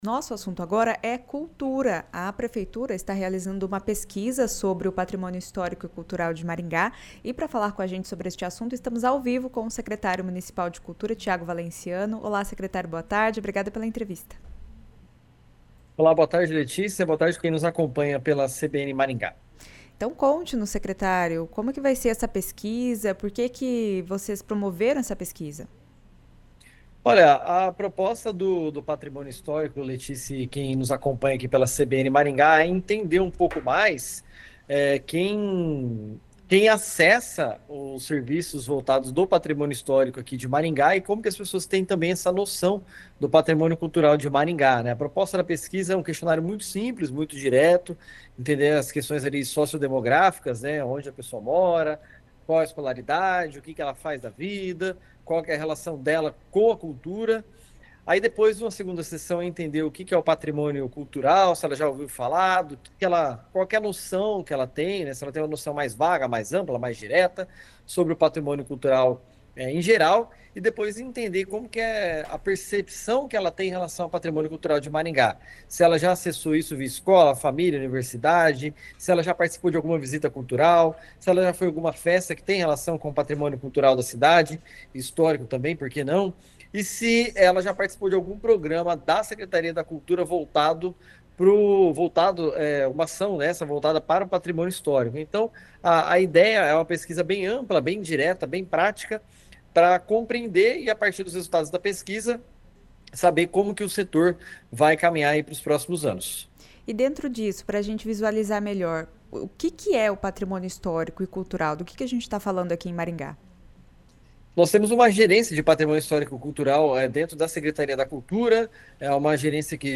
A pesquisa sobre Patrimônio Histórico e Cultural de Maringá tem o objetivo é identificar o grau de percepção da população sobre temas como tombamento, registro e salvaguarda de bens culturais. É  o que explica o secretário Tiago Valenciano.
O secretário também falou sobre o Prêmio Aniceto Matti e sobre a Virada Cultural que será realizada nos dias 15 e 16 de novembro. Ouça a entrevista.